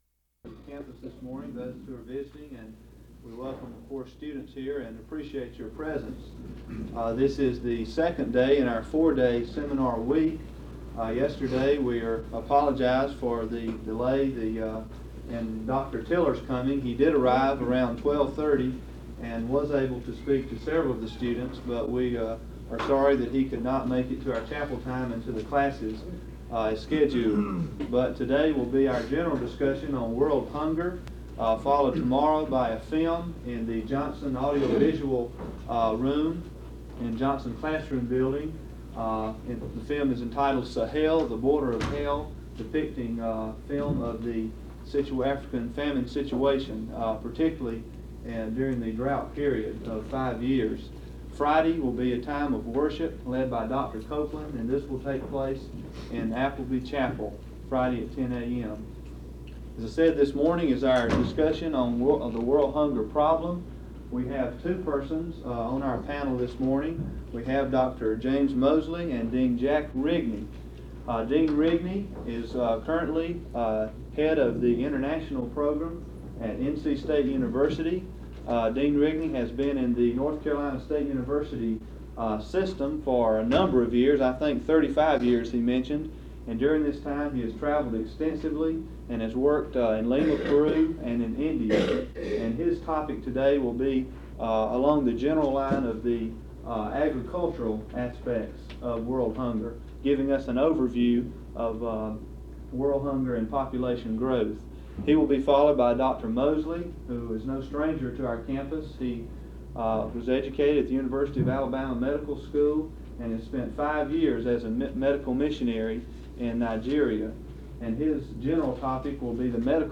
Panel discussion on world hunger